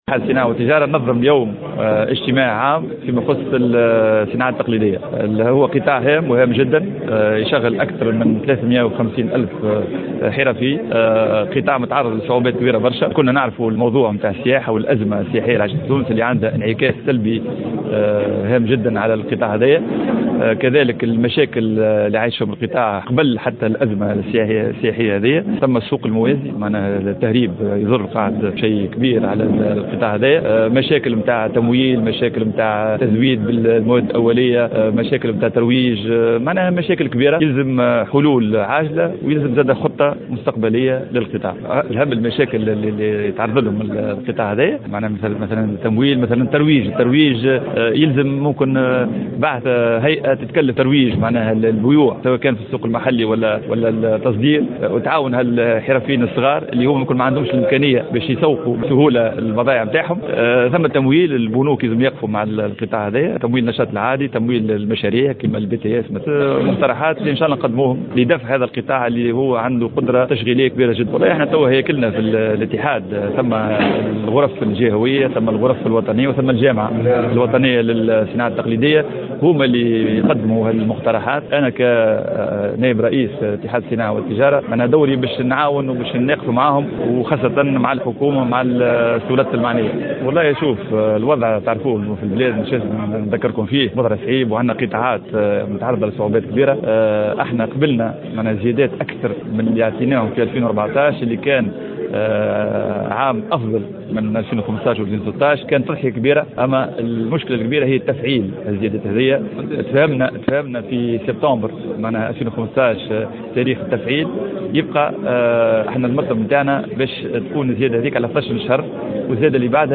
على هامش انعقاد اجتماع عام لمهنيي الصناعات التقليدية بالمدينة العتيقة بتونس